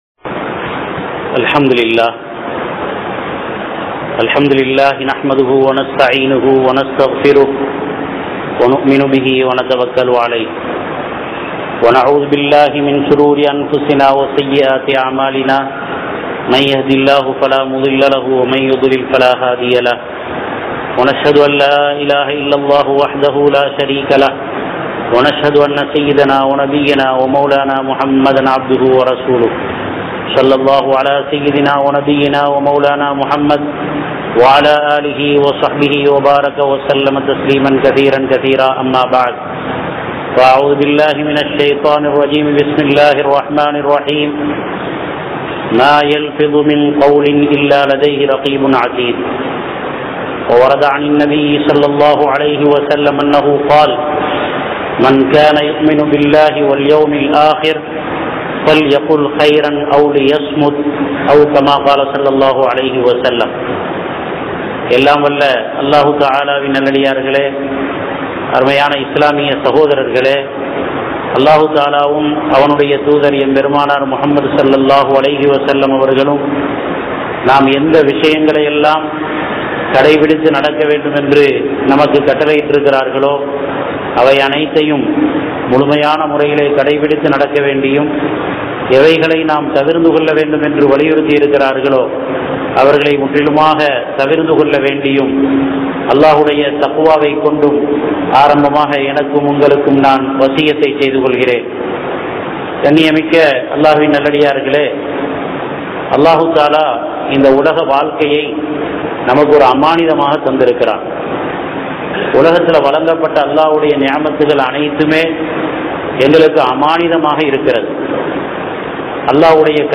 Amaanitham(அமானிதம்) | Audio Bayans | All Ceylon Muslim Youth Community | Addalaichenai